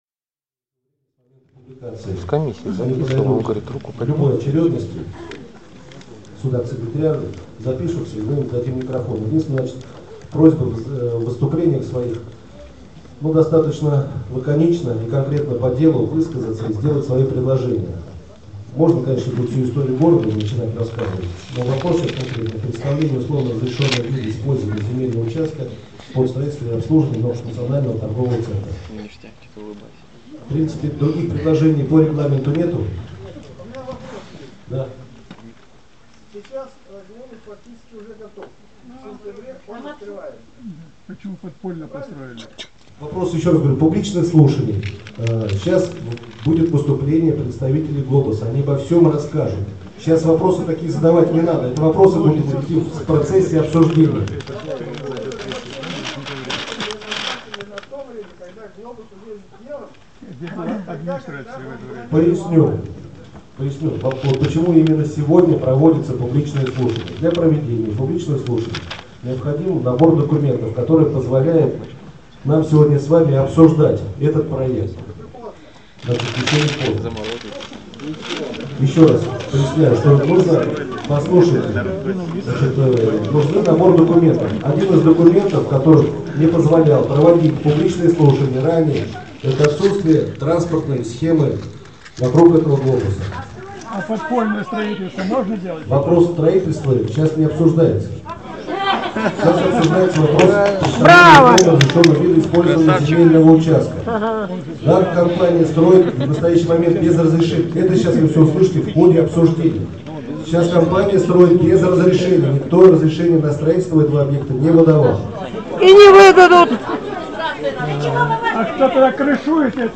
Слушания по Глобусу